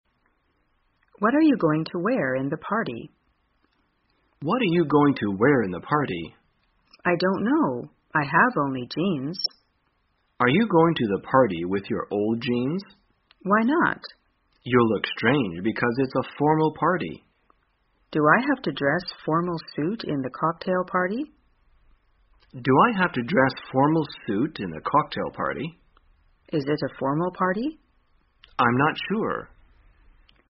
在线英语听力室生活口语天天说 第281期:怎样选择服装的听力文件下载,《生活口语天天说》栏目将日常生活中最常用到的口语句型进行收集和重点讲解。真人发音配字幕帮助英语爱好者们练习听力并进行口语跟读。